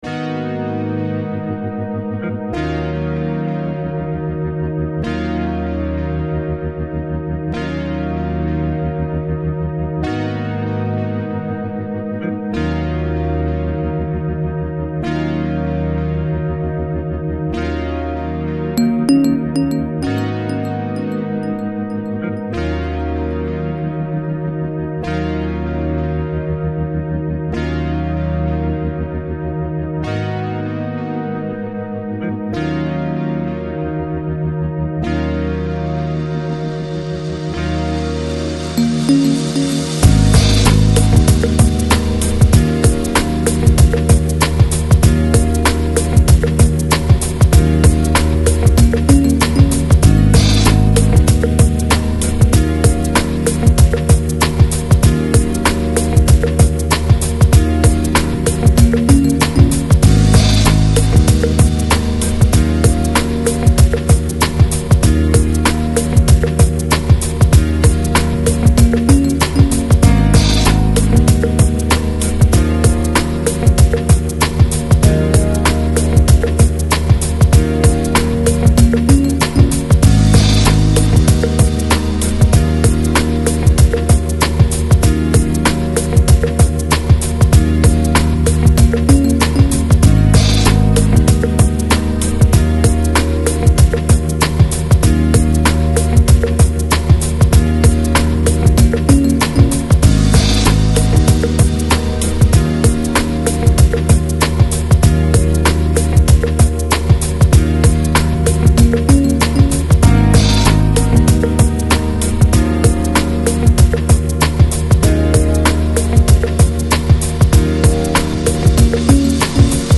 Chill Out, Lounge, Downtempo